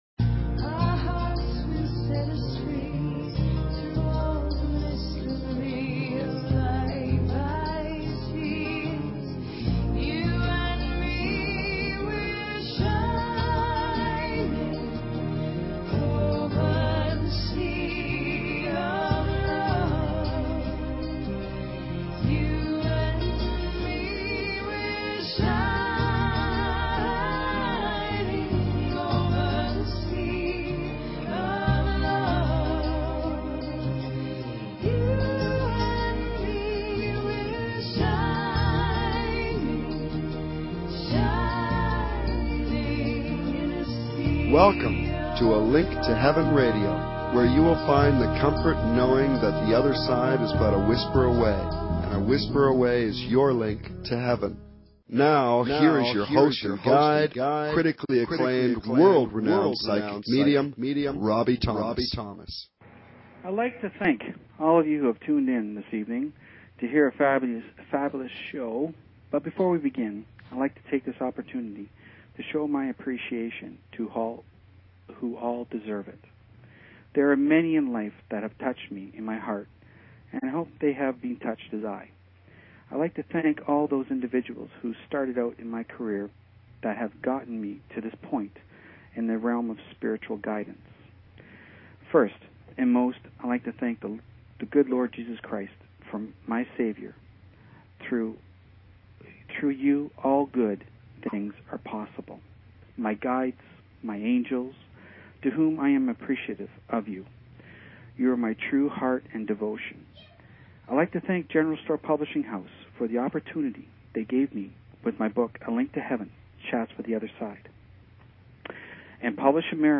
Talk Show Episode, Audio Podcast, A_Link_To_Heaven and Courtesy of BBS Radio on , show guests , about , categorized as